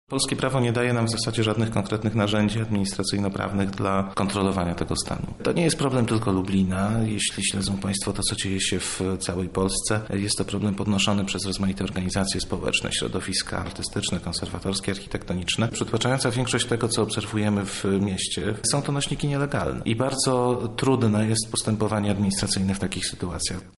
konserwator